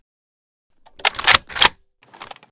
vssk_chamber.ogg